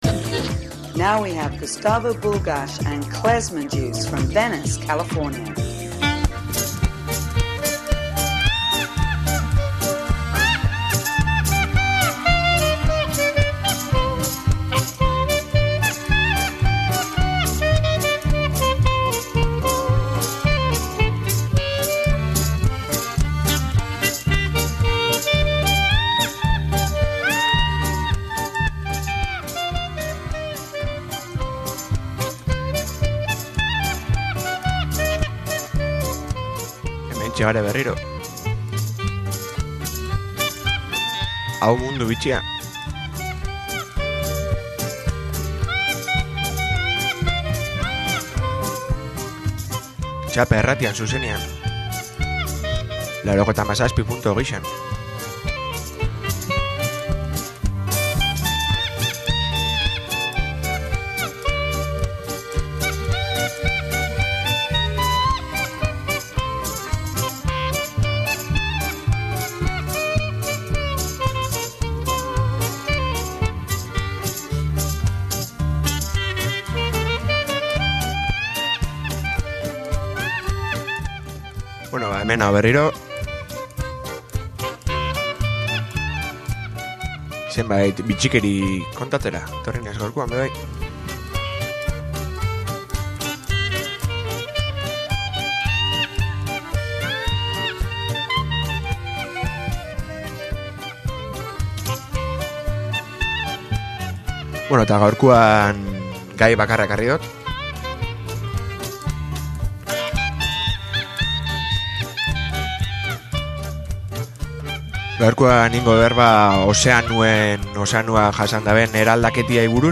Hau Mundu Bitxia planeta honetako izaki bizidunen hainbat bitxikeria edo “kaskarrillo” azaltzen dituen irratsaio bat da. Bertan bai animali naiz landare ezberdinak deskribatzen dira, beraien aparteko ezaugarri eta ahalmenak aipatuz.